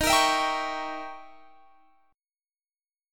Eb7b9 Chord
Listen to Eb7b9 strummed